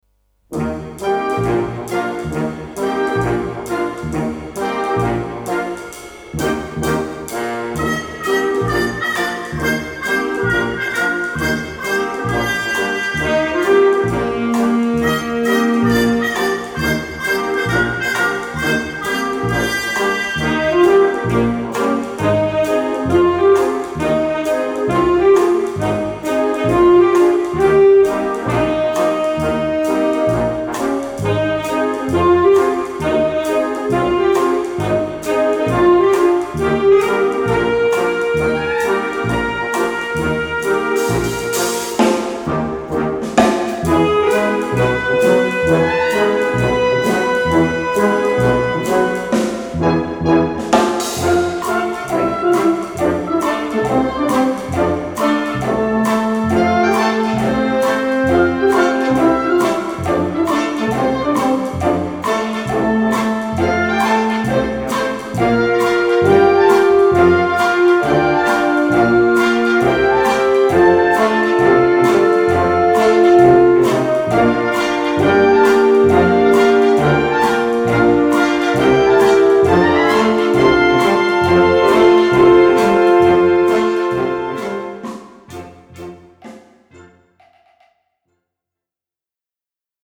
Wind Band (harmonie)
Film